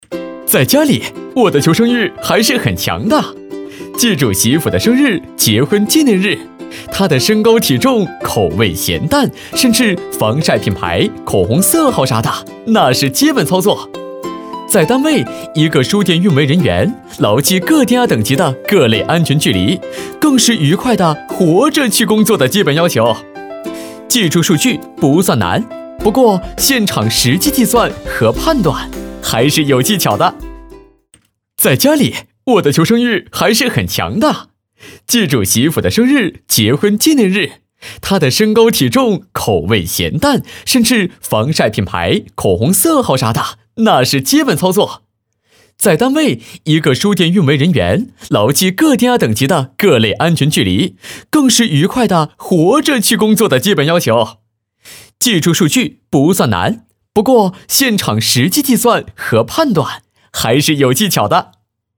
男166号